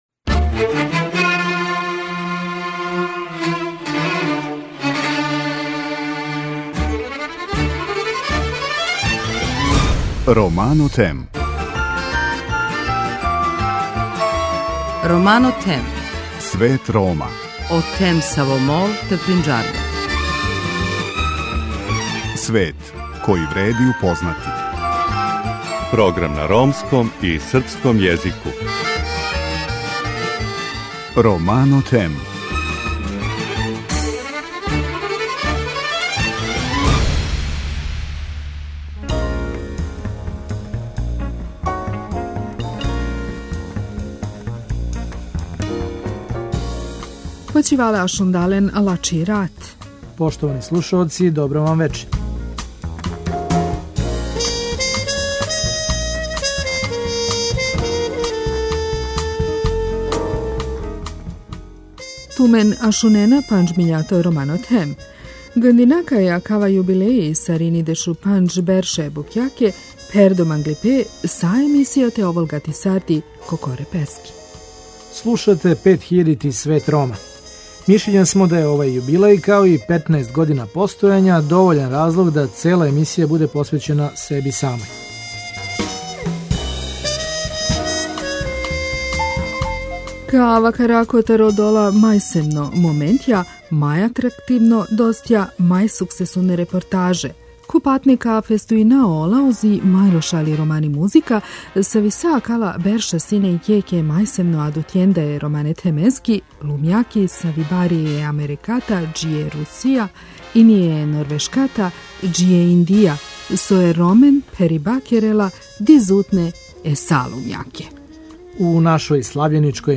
Подсетићемо се најлепших тренутака, најатрактивнијих гостију који су говорили о Ромима и за вас бирали најлепшу ромску музику. Прелиставамо 15 година исписиване „странице" са најуспелијим репортажама и дирљивим цртицама из живота Рома, које дају другачију слику о припадницима овог народа.